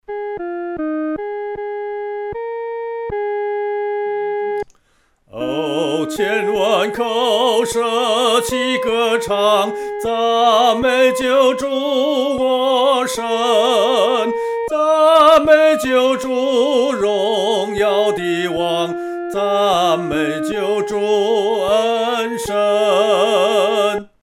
独唱（第一声）
万口欢唱-独唱（第一声）.mp3